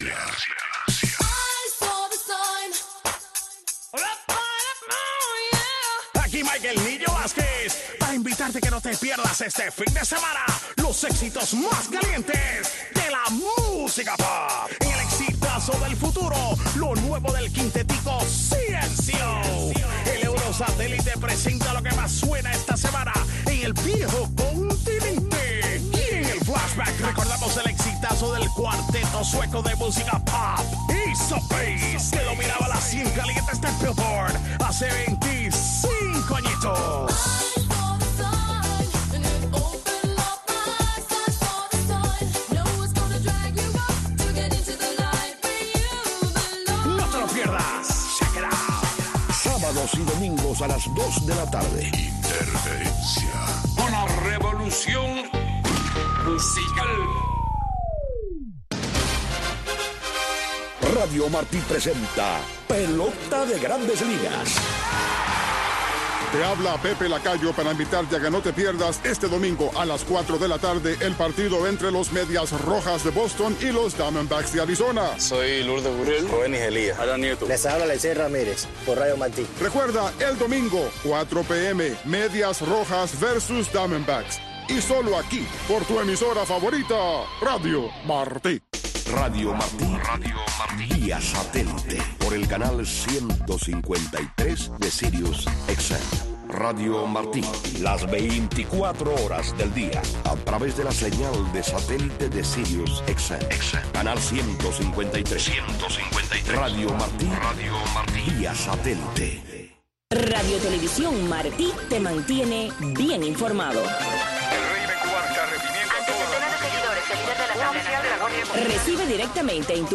Radio Marti te presenta todos los sábados y domingos entre 6 y 8 de la mañana el bloque religioso “La Religión en Martí” en donde te presentaremos diferentes voces de académicos, pastores y hombres de fe que te traerán la historia y la palabra esperanzadora del señor.